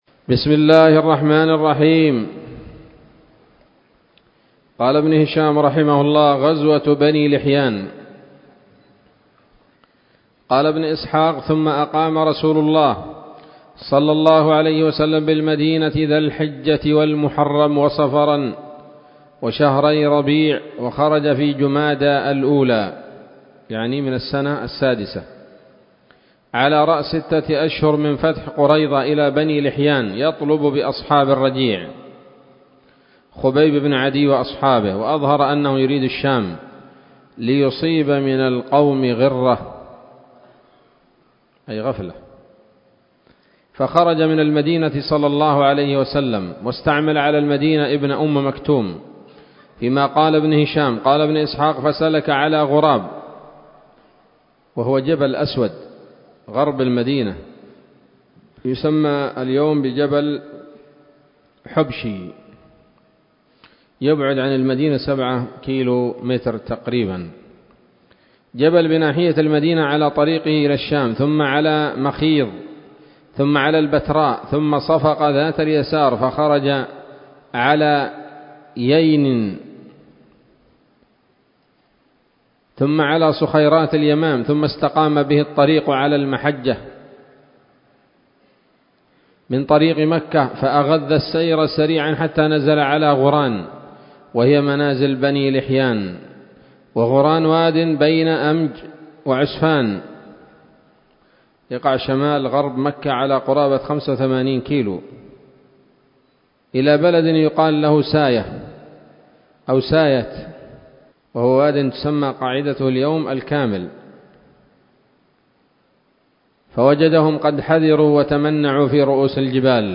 الدرس التاسع عشر بعد المائتين من التعليق على كتاب السيرة النبوية لابن هشام